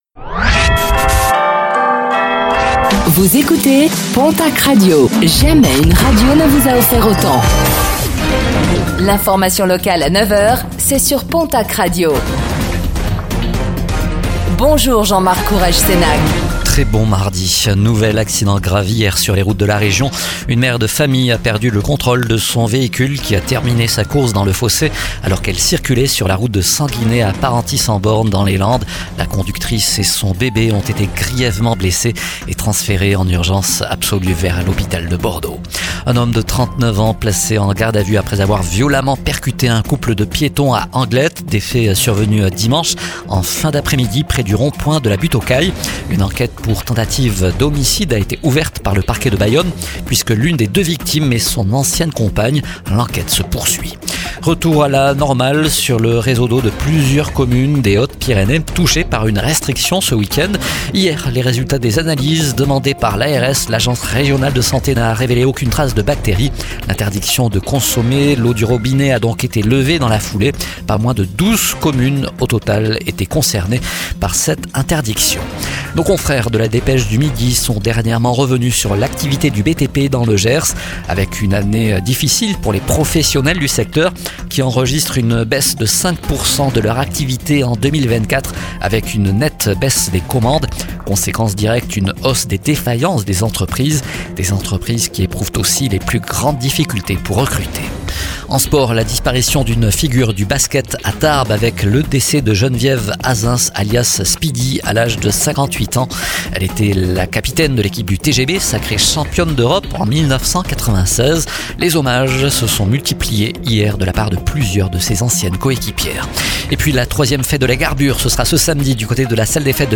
Réécoutez le flash d'information locale de ce mardi 18 novembre 2025